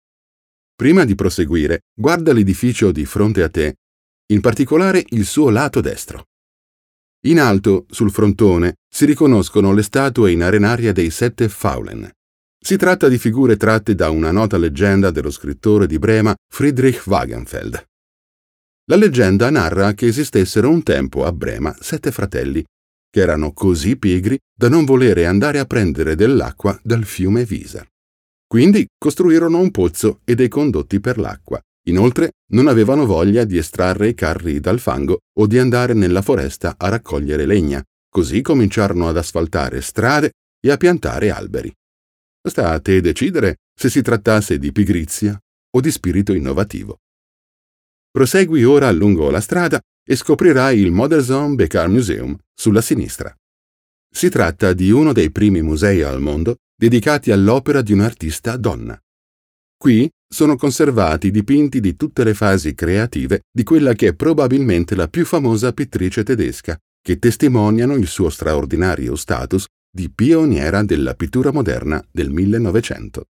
Male
Adult (30-50)
Tour Guide
Brema Touristic Guide
0304brema-demo.mp3